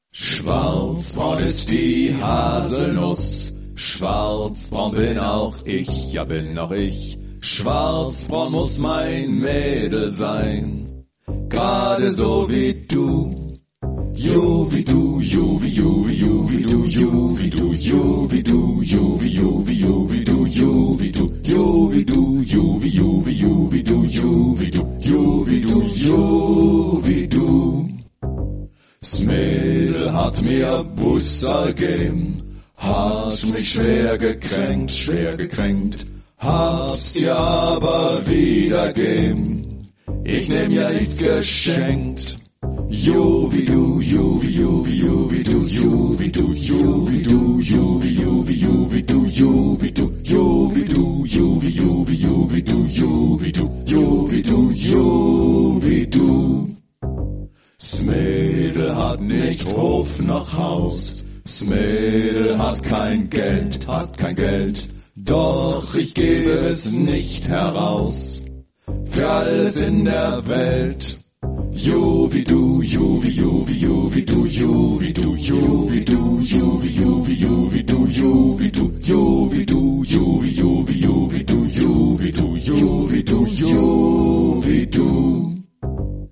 Обязательно послушайте вот это современное и такое приятное исполнение старой народной песенки в обработке Детлефа Кордеса: